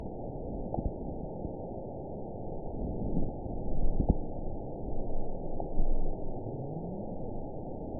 event 916732 date 01/18/23 time 22:20:26 GMT (2 years, 3 months ago) score 9.63 location TSS-AB10 detected by nrw target species NRW annotations +NRW Spectrogram: Frequency (kHz) vs. Time (s) audio not available .wav